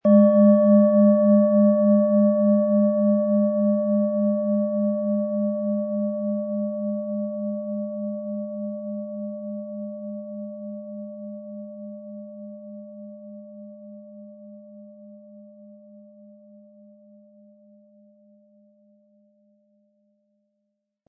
Von Hand getriebene tibetanische Planetenschale Saturn.Weitergegebene Fertigungs-Kenntnisse innerhalb von Familien, die seit Jahrhunderten Klangschalen herstellen, machen unsere tibetische Schalen so außerordentlich.
• Mittlerer Ton: Mond
PlanetentöneSaturn & Mond
MaterialBronze